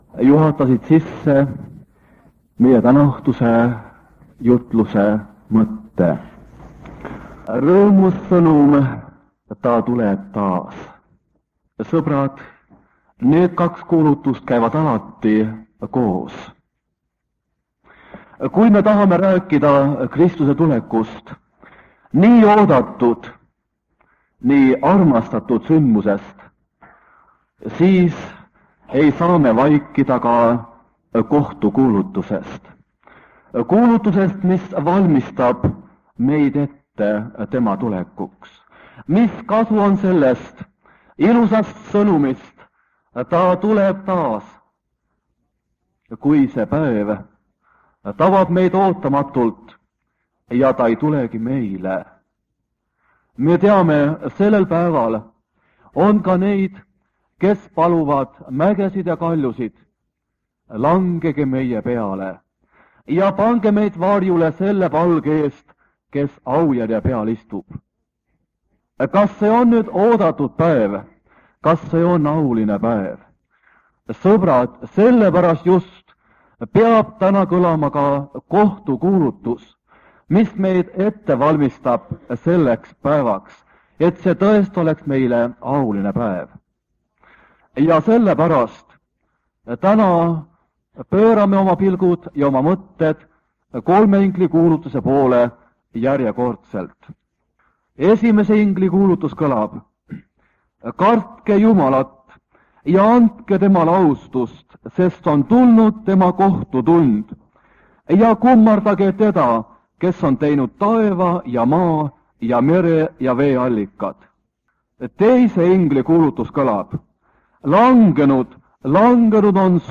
Jutlus vanalt lintmaki lindilt 1978 aastast.